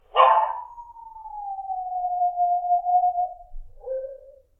하울링
howling.mp3